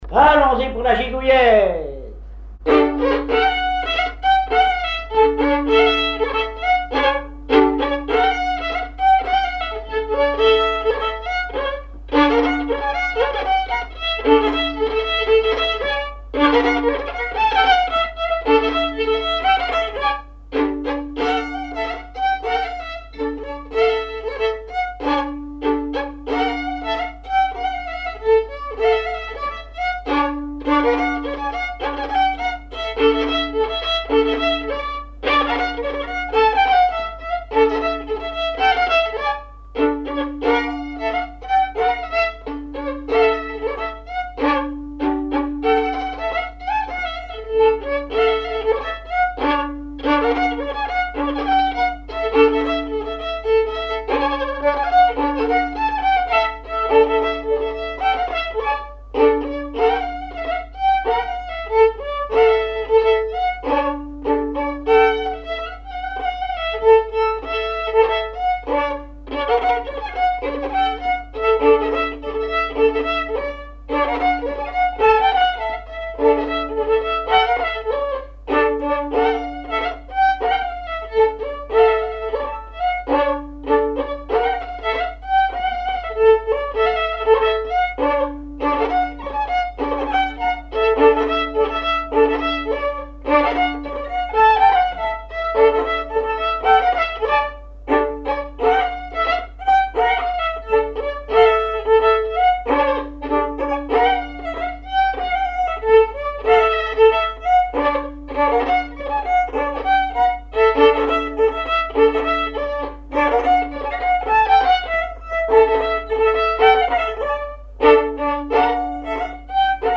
Chants brefs - A danser
recherche de répertoire de violon pour le groupe folklorique
Pièce musicale inédite